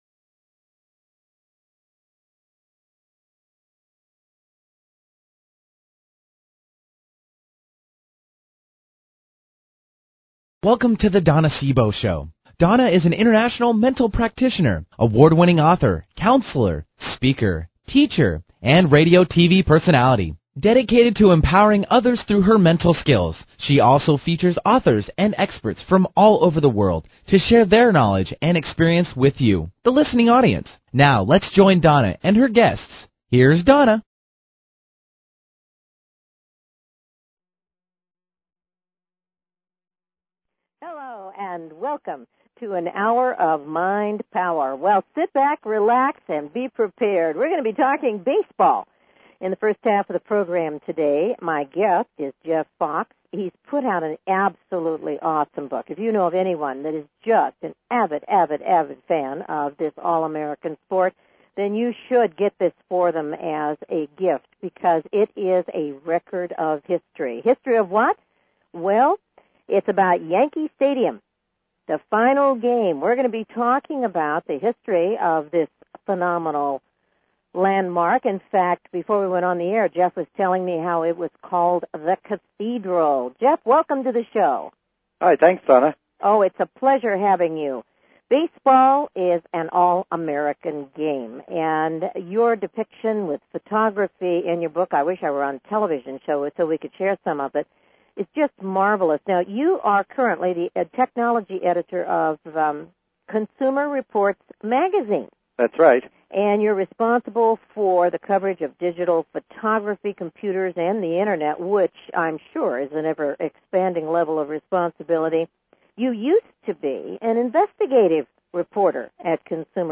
Talk Show Episode
If you are a baseball fan you'll not want to miss this wonderful interview with a man who has phenomenal stories to share about this world famous stadium.
Her interviews embody a golden voice that shines with passion, purpose, sincerity and humor.